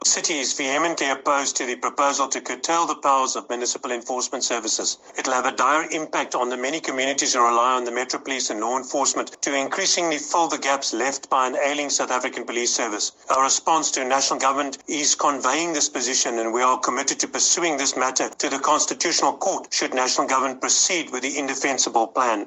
The latest proposal by the national government is to limit the city enforcement services’ powers to by-law and traffic enforcement only. Mayoral committee member for Safety and Security, JP Smith, says this means they will no longer play a part in crime prevention, as conveyed in an entire section of the national draft policy devoted to this subject: